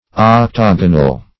Octogonal \Oc*tog"o*nal\, a.